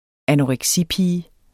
Udtale [ anoʁεgˈsi- ]